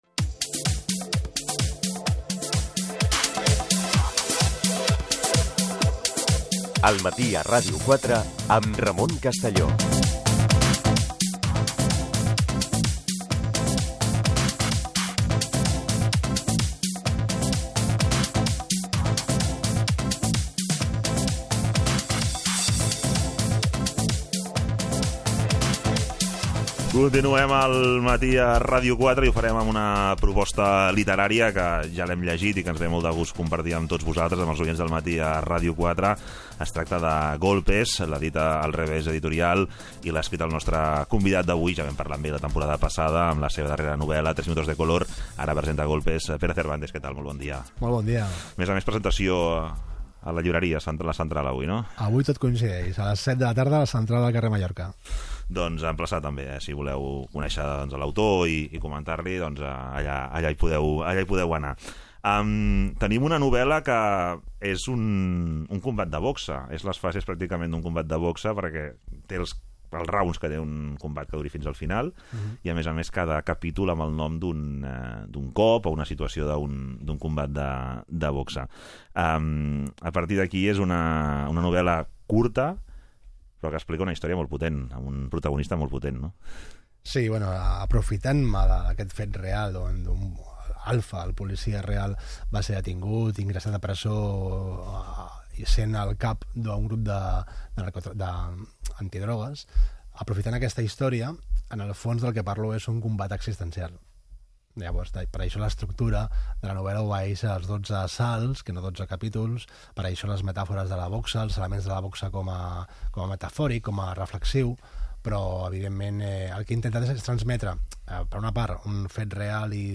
Indicatiu, entrevista
Info-entreteniment